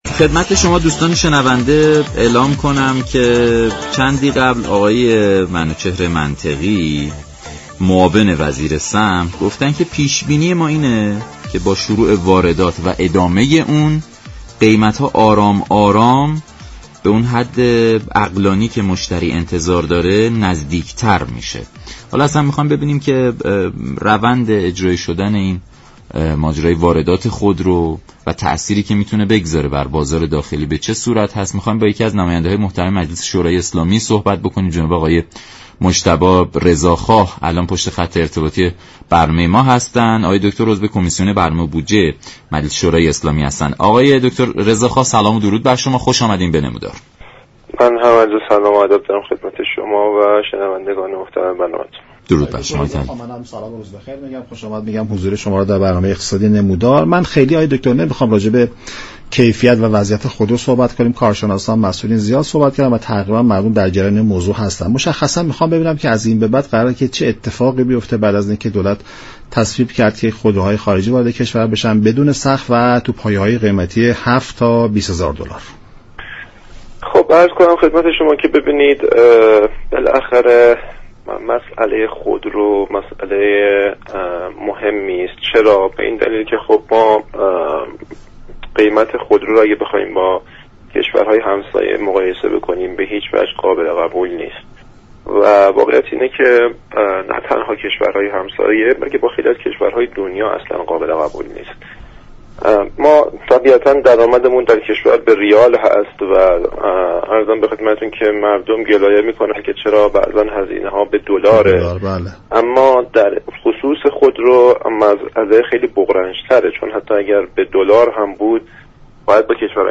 به گزارش شبكه رادیویی ایران، «مجتبی رضا خواه» عضو كمیسیون برنامه و بودجه مجلس در برنامه «نمودار» به بحث آزاد سازی واردات خودرو پرداخت و گفت: اینكه برخی ها متعقدند اجرایی شدن طرح آزاد سازی خودرو، قیمت ارز را افزایش خواهد داد یك توجیه نادرستی است، اجرایی شدن سیاست ارز ترجیحی، نیاز كشور را به ارز كاهش خواهد داد.